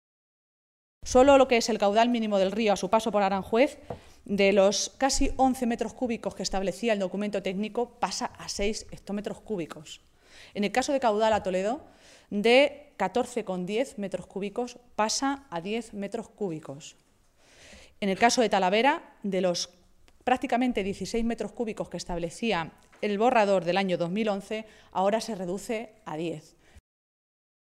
Maestre hacía estas declaraciones en una comparecencia ante los medios de comunicación, en Toledo, en la que valoraba la propuesta de Plan Hidrológico del Tajo que ha publicado hoy en el Boletín Oficial del Estado el Ministerio de Agricultura.
Cortes de audio de la rueda de prensa